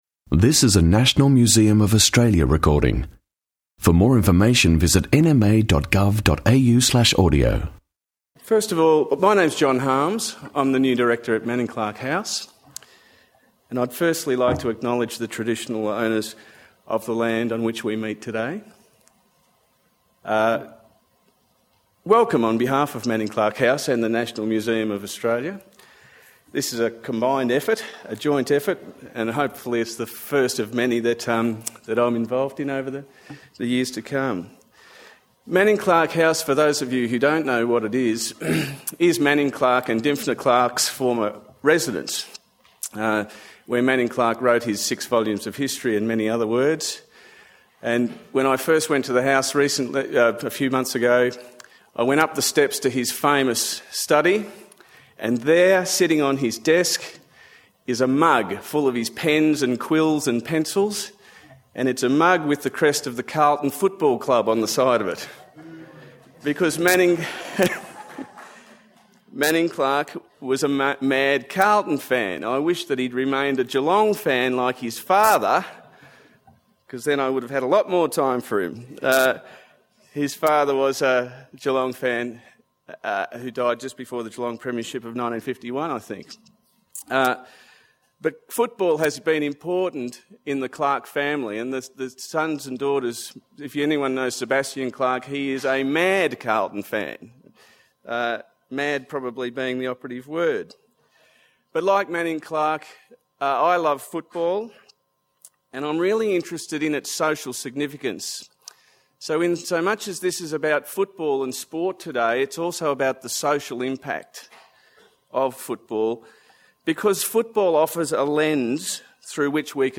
A forum on how Indigenous Australians have enriched Australian Rules football, and the social significance of their participation. Speakers include players, academics and sports commentators.